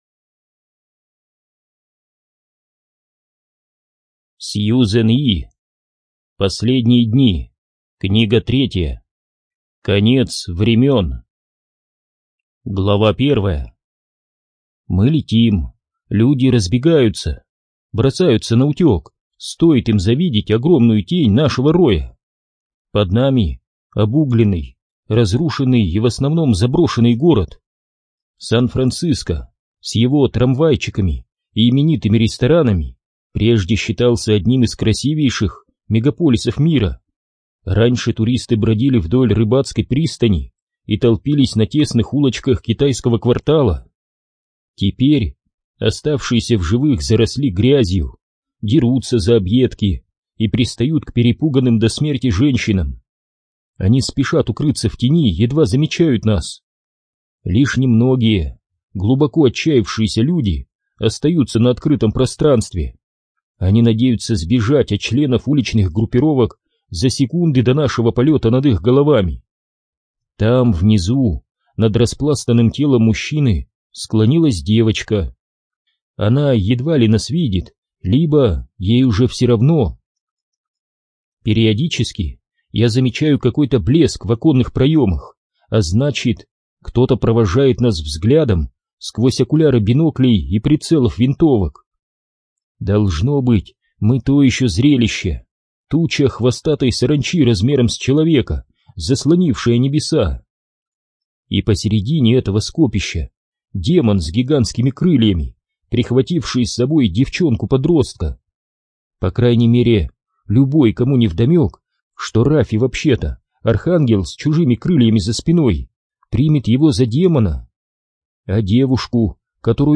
ЖанрФантастика, Фэнтези